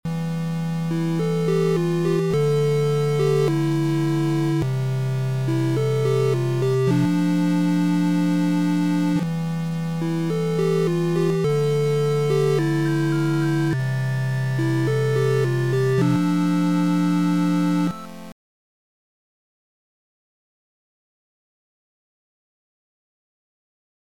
Short 8bit game over music